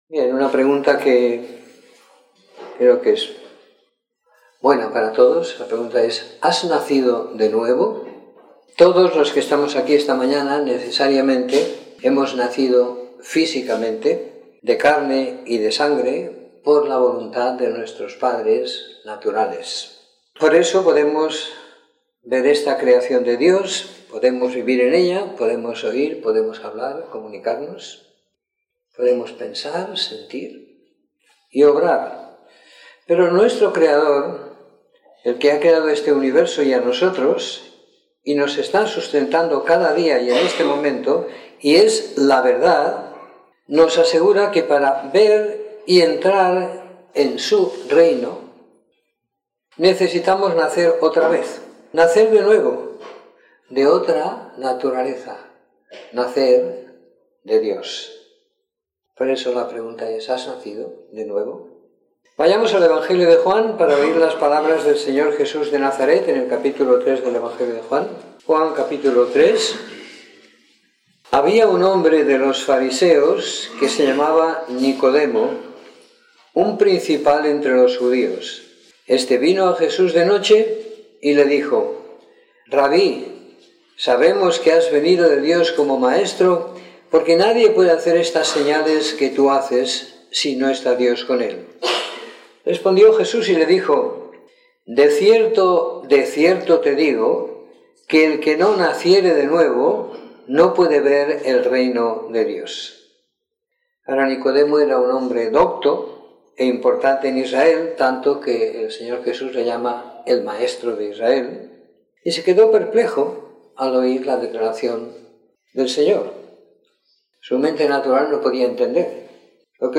Domingo por la Mañana . 10 de Septiembre de 2017